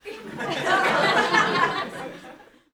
LaughterCrowd AR03_36_1.wav